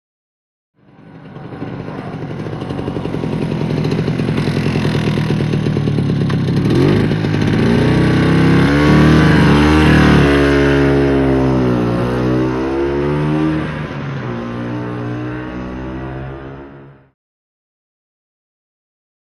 Moped; Idle / Away; Scooter Up To Mic. Short Idling And Away.